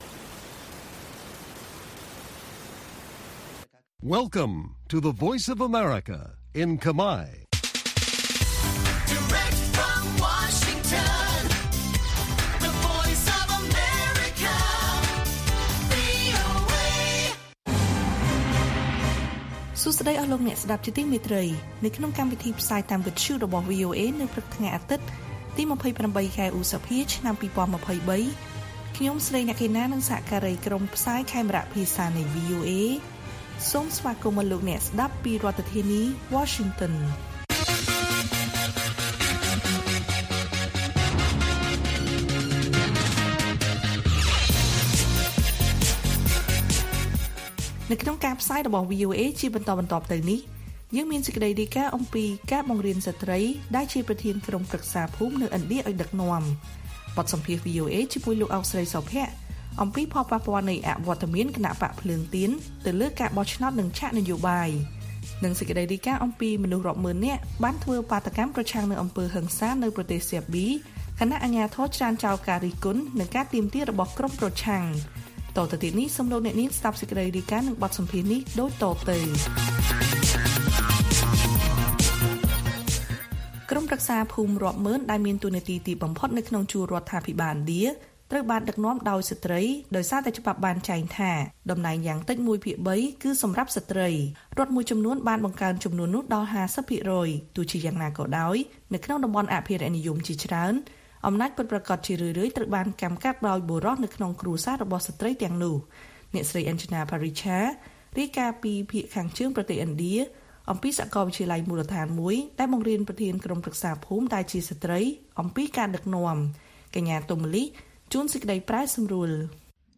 ព័ត៌មានពេលព្រឹក ២៨ ឧសភា